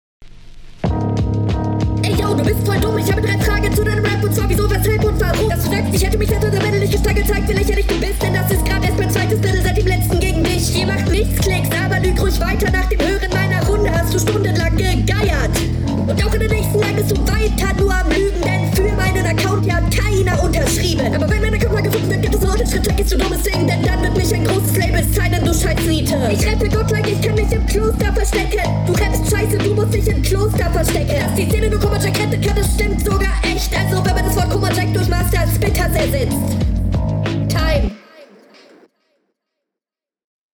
Hattest funny Zeilen und paar coole Flowpassagen dabei.